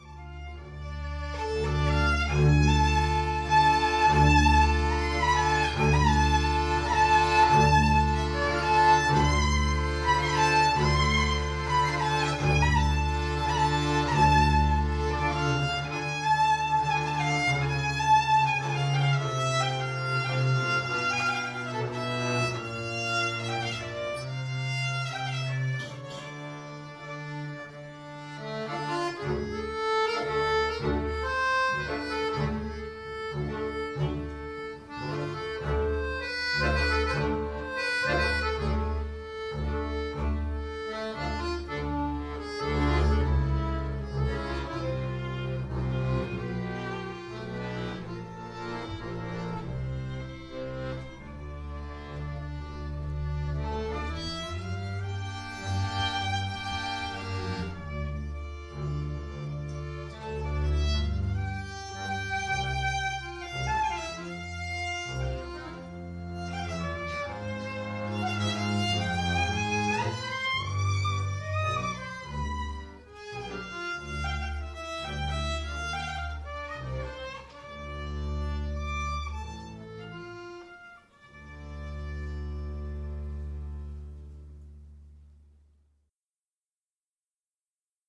Klezmajeur, klezmer ensemble, contact